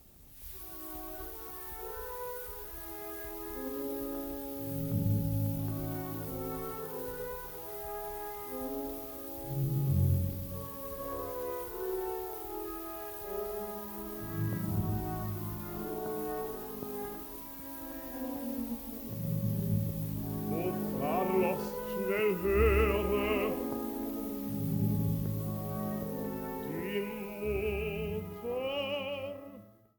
Opernszenen
Gewandhausorchester Leipzig, Gustav Brecher, Paul Schmitz
Der zweite Teil der CD-Serie enthält auf zwei CDs insgesamt 15 Ausschnitte von acht Opern in Aufnahmen von 1929 bis 1945.